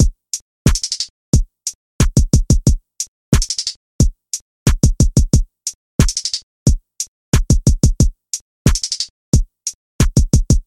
没有808的硬式陷阱鼓
Tag: 90 bpm Trap Loops Drum Loops 1.79 MB wav Key : Unknown